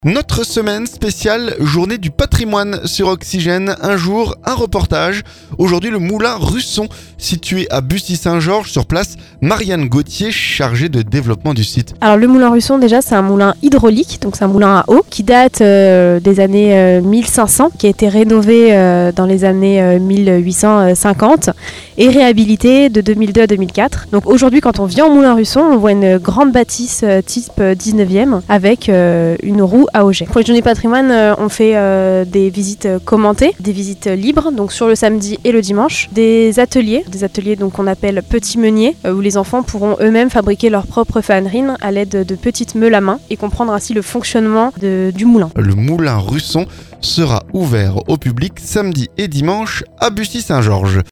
Un jour, un reportage.